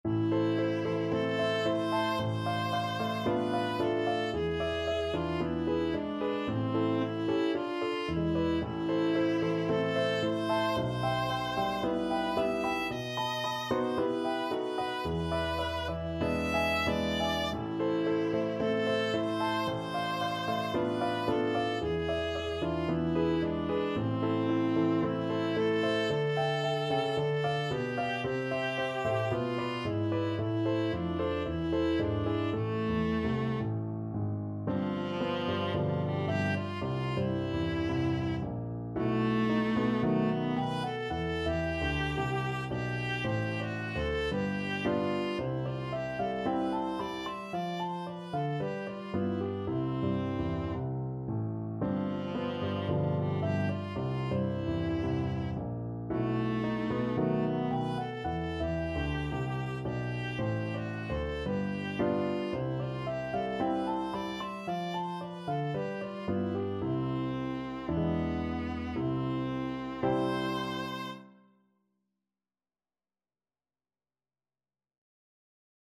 Viola
A minor (Sounding Pitch) (View more A minor Music for Viola )
Moderato il canto = c.56
2/4 (View more 2/4 Music)
Classical (View more Classical Viola Music)
Cuban